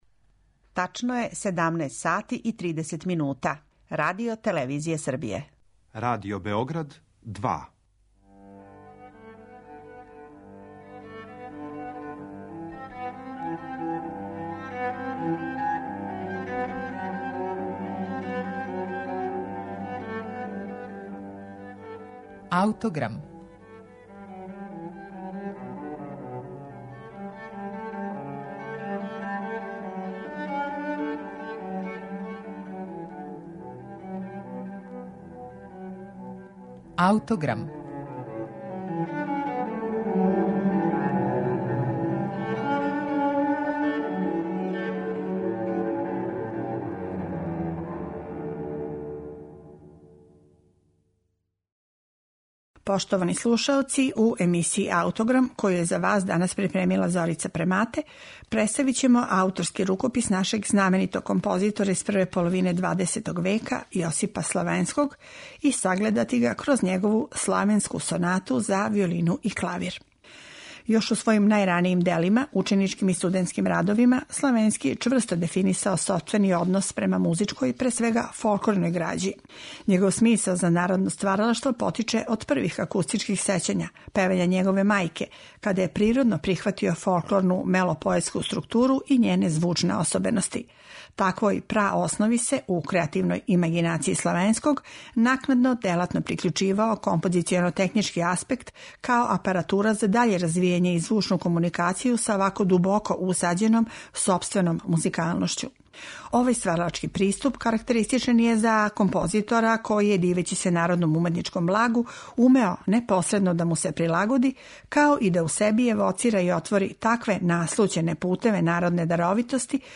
Вашој пажњи ћемо препоручити све три сонате нашег музичког великана ЈОСИПА СЛАНЕВСКОГ, а започећемо са његовом „Славенском сонатом“ за виолину и клавир из 1924. године.